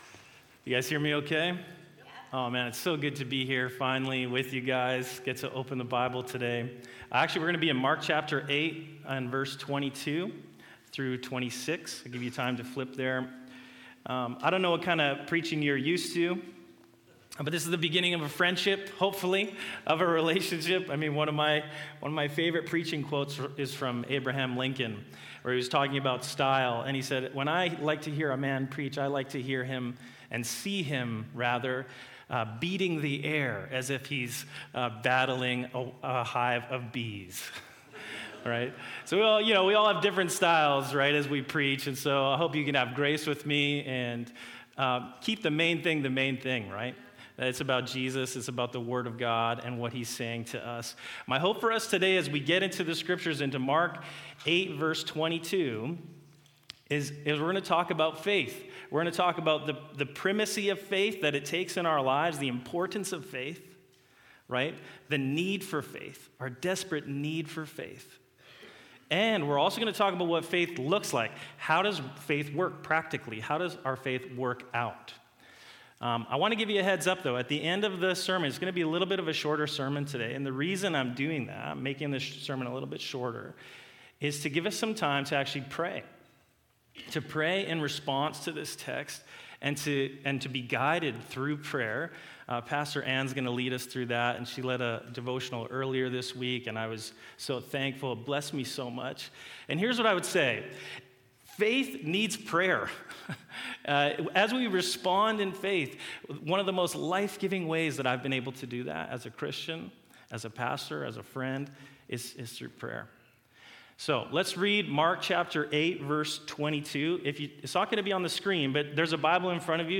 Sermons | North Shore Alliance Church